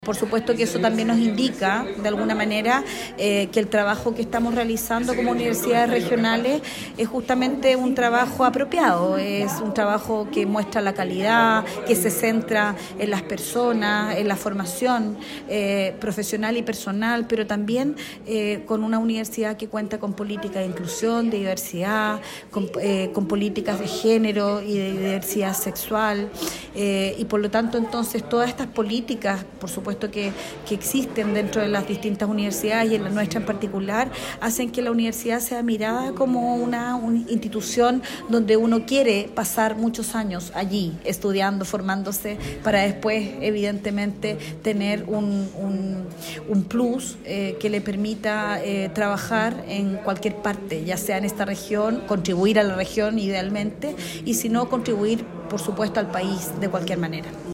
Mientras desayunaban en un mesón amplio en el Salón Biobío de la Delegación Presidencial Regional, las autoridades preguntaron a las y los estudiantes a qué carreras y universidades quieren postular, lo que permitió constatar que la mayoría tiene intenciones de quedarse en la Región del Biobío.